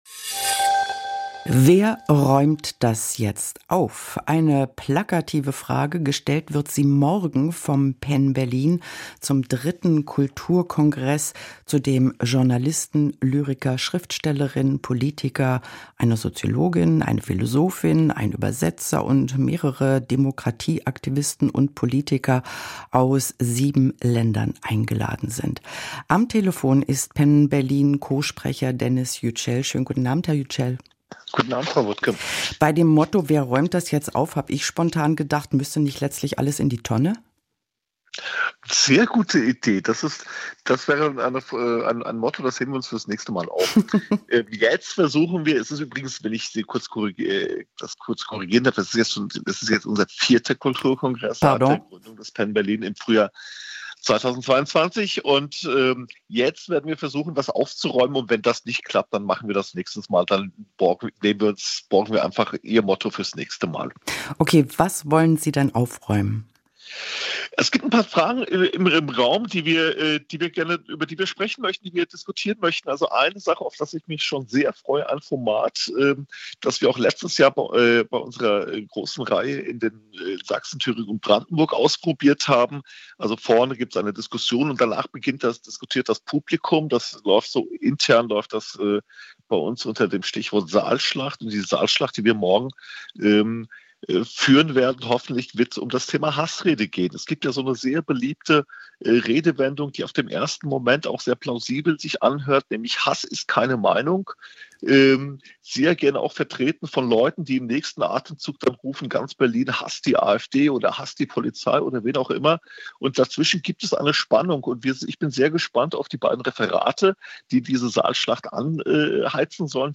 radio 3 [rbb], Radio 3 am Morgen, Gespräch